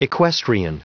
Prononciation du mot equestrian en anglais (fichier audio)
Prononciation du mot : equestrian